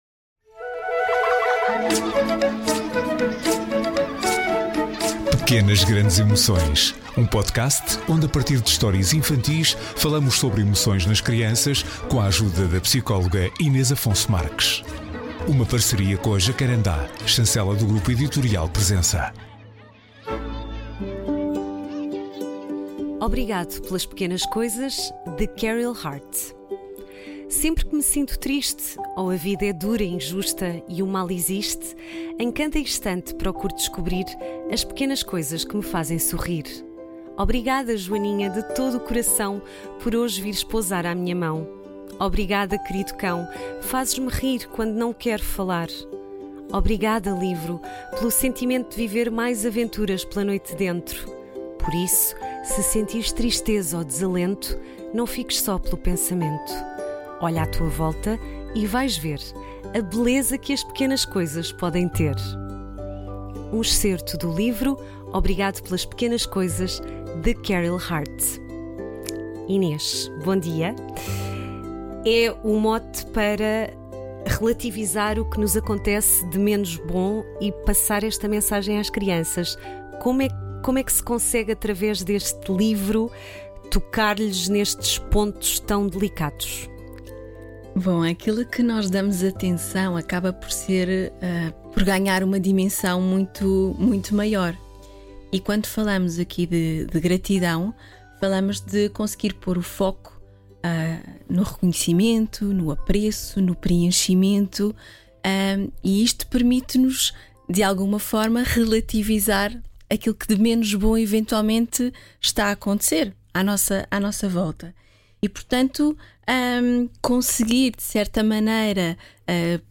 Uma conversa